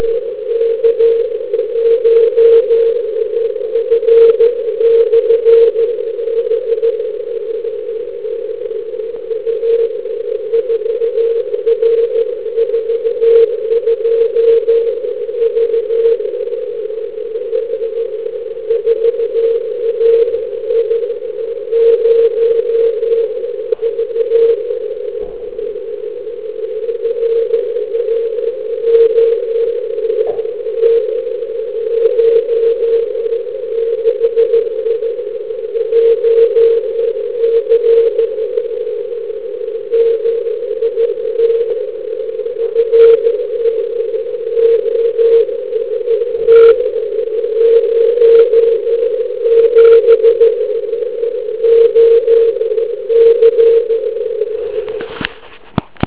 Ovšem při rozpadání D vrstvy a jejím vzniku je signál majáku na jeden odraz slyšitelný.